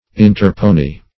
Search Result for " interpone" : The Collaborative International Dictionary of English v.0.48: Interpone \In`ter*pone"\, v. t. [L. interponere; inter between + ponere to place.